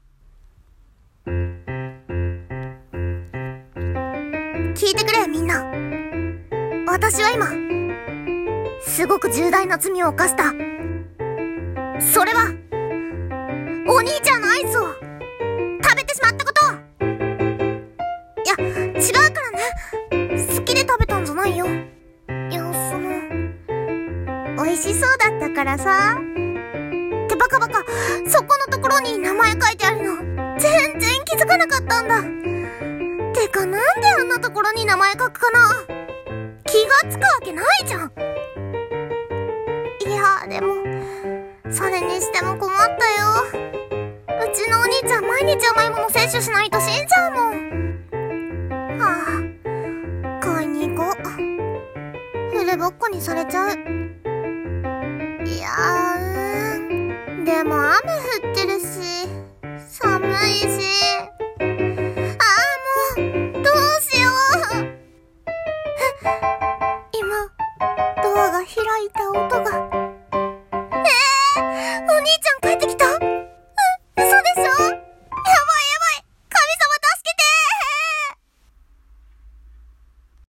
【声劇】 アイス食べちゃった